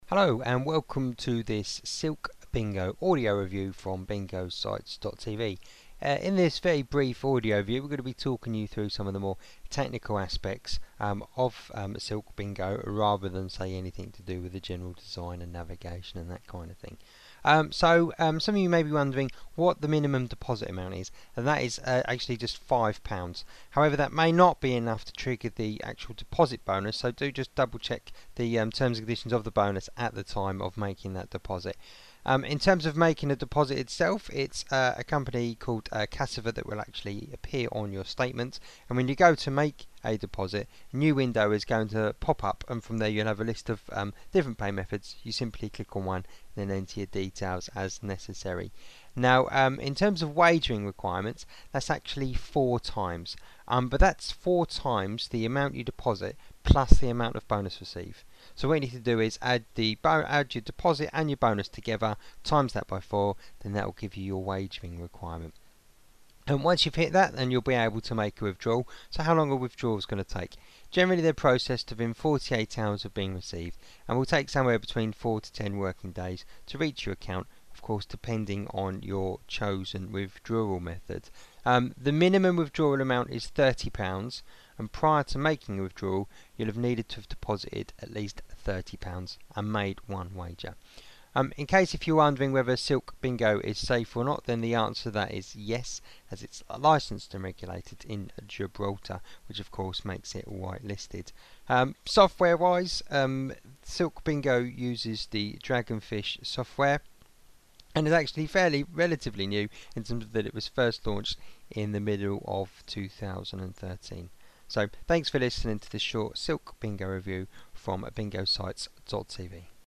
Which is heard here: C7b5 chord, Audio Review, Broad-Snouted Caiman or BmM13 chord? Audio Review